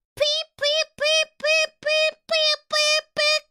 Korone Beep